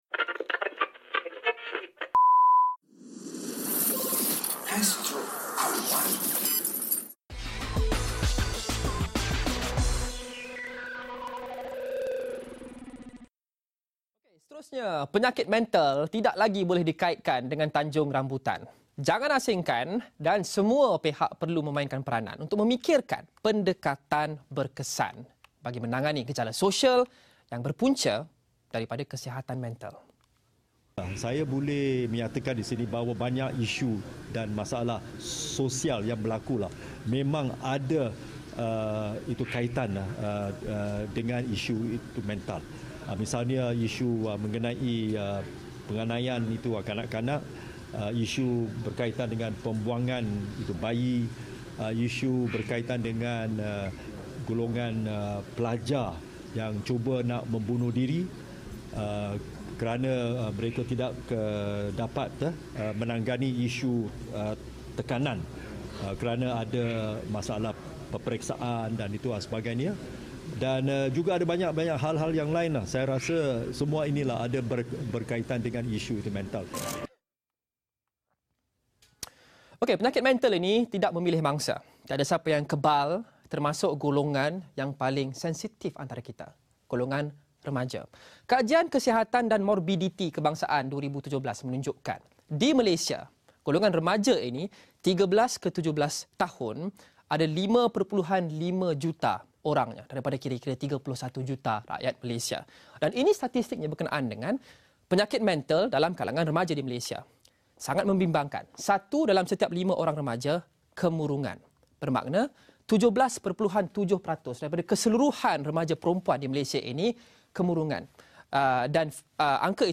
0:31 - Tan Sri Lee Lam Thye, Penaung Persatuan Psikiatri Malaysia